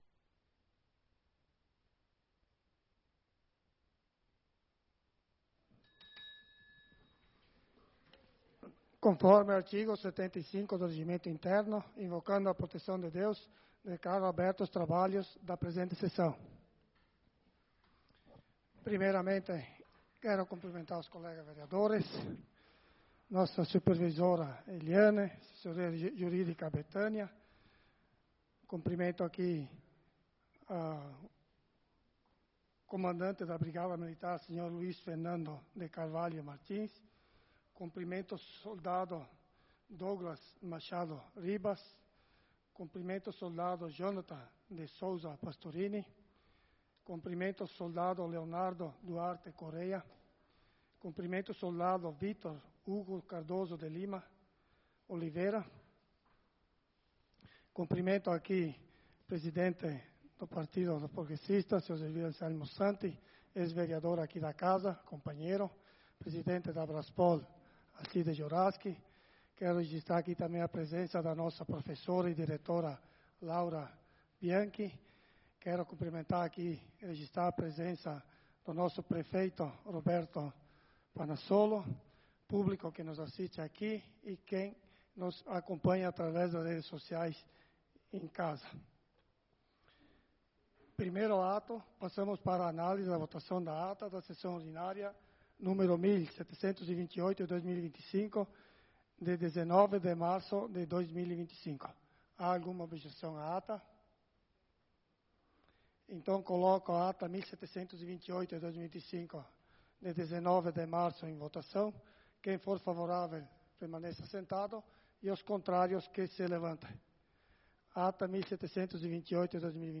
Sessão Ordinária do dia 26/03/2025